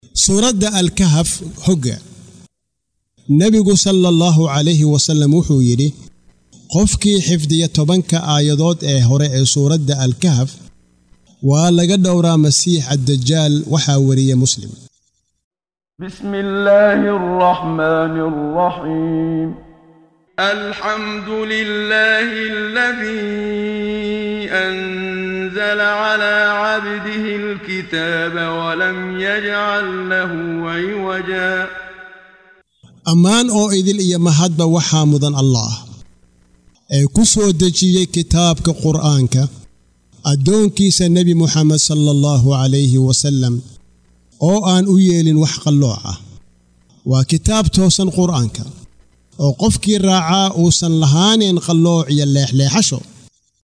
قراءة صوتية باللغة الصومالية لمعاني سورة الكهف مقسمة بالآيات، مصحوبة بتلاوة القارئ محمد صديق المنشاوي - رحمه الله -.